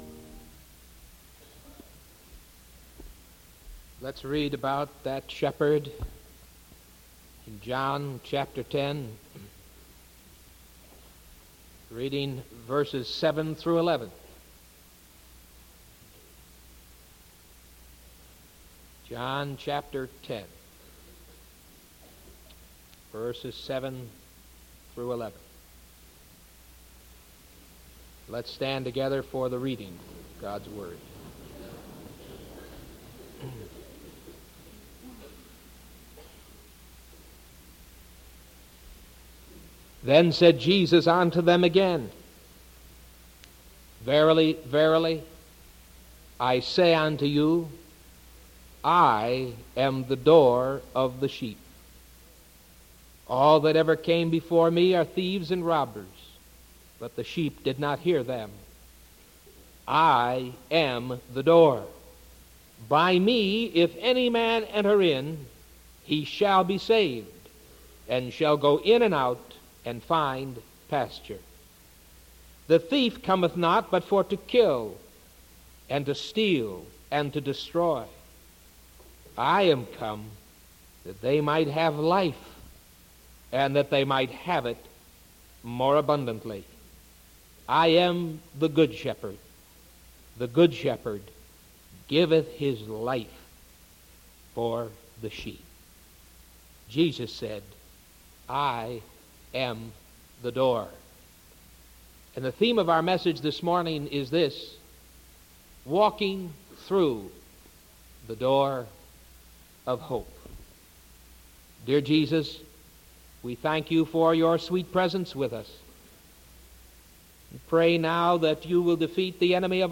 Sermon March 19th 1978 AM